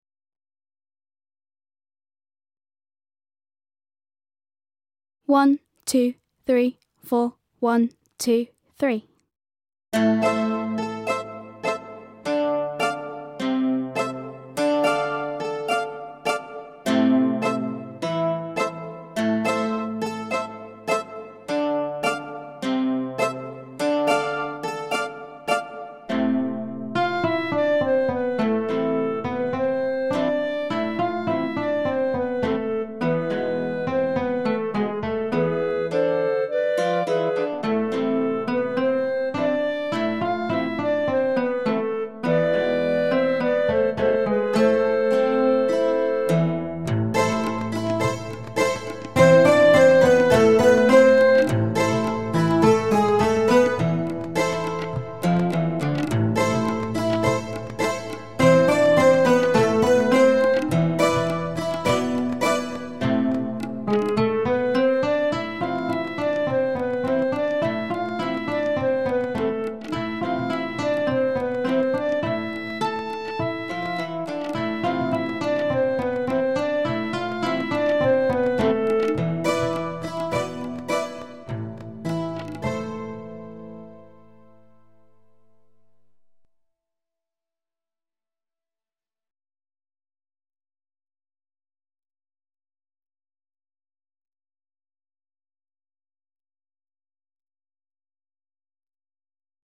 37 Usküdar (Backing Track)